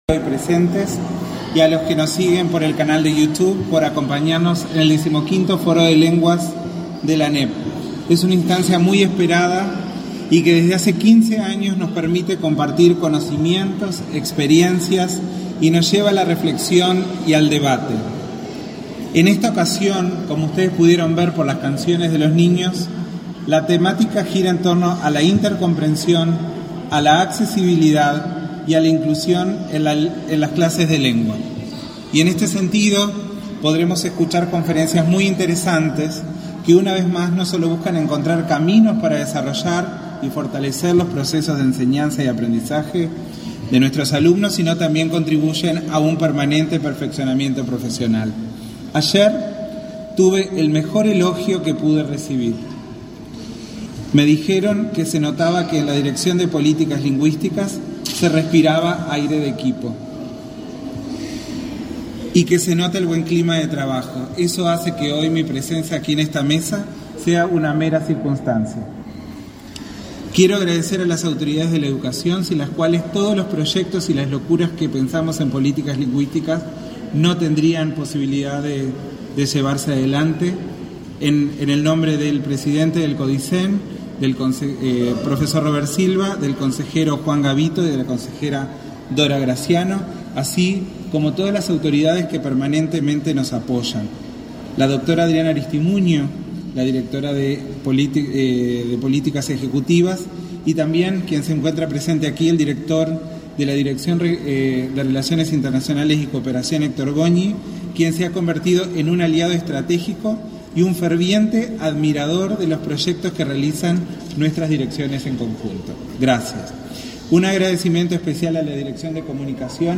Conferencia de prensa por la apertura del 15.° Foro de Lenguas
Conferencia de prensa por la apertura del 15.° Foro de Lenguas 11/10/2022 Compartir Facebook X Copiar enlace WhatsApp LinkedIn La Administración Nacional de Educación Pública (ANEP), a través de la Dirección de Políticas Lingüísticas, abrió, este 11 de octubre, el 15.° Foro de Lenguas. Participaron el presidente de la ANEP, Robert Silva, y del director de Políticas Lingüistas, Aldo Rodríguez.